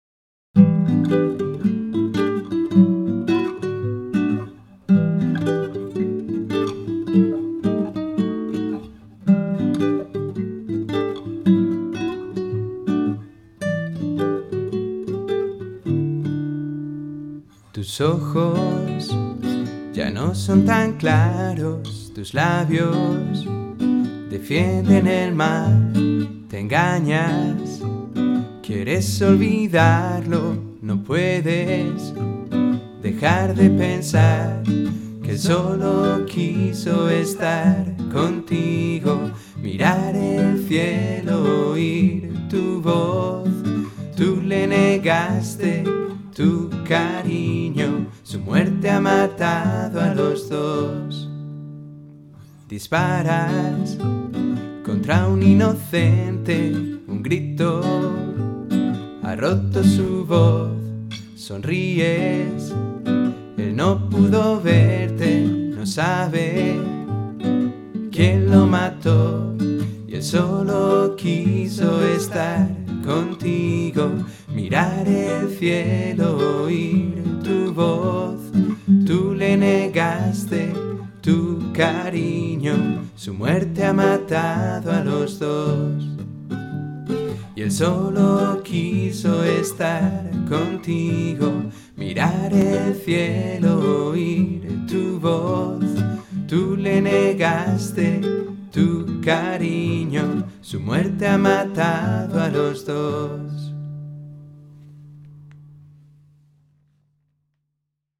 Two guitars, two voices, too much noise? It is a short song, trying not to be boring.